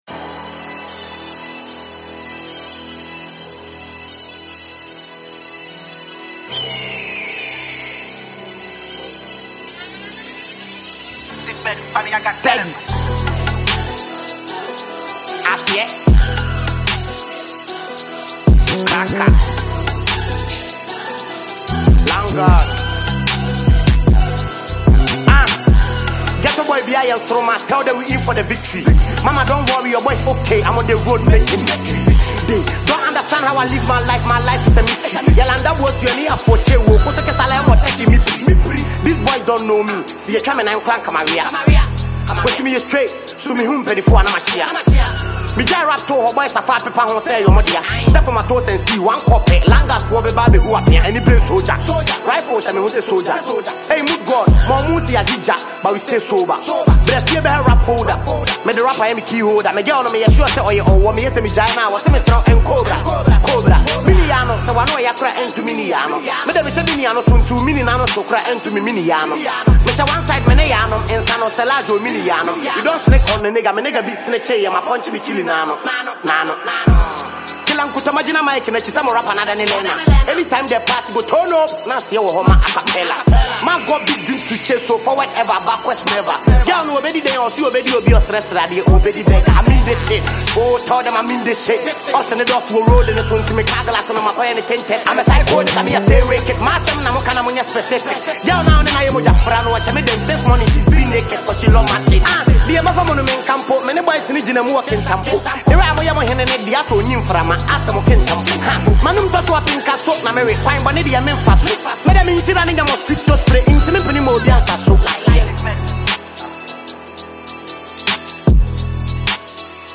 Ghanaian rap sensation